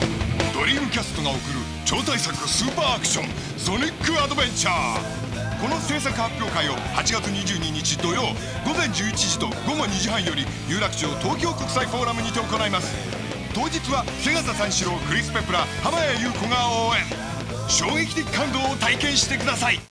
ラジオ版
今回発表会をやるにあたり、よりたくさんのユーザーに来ていただきたいと思い、初めてラジオＣＦに出てみました。